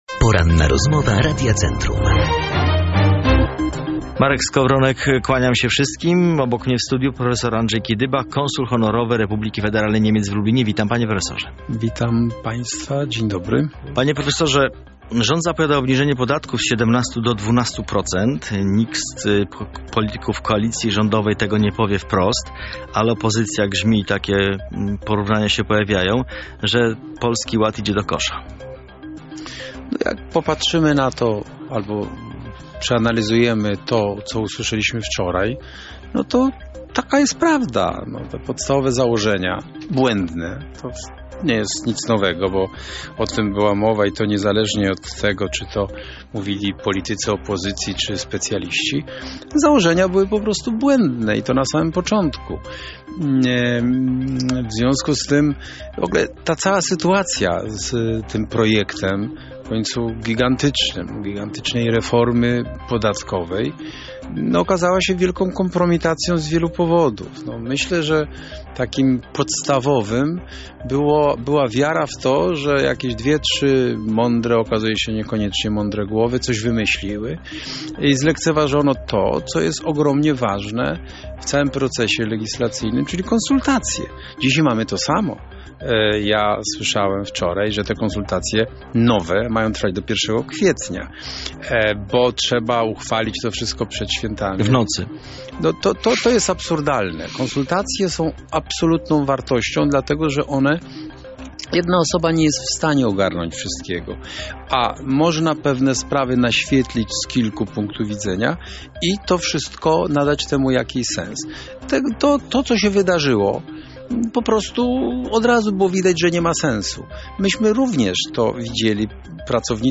Poranna Rozmowa Radia Centrum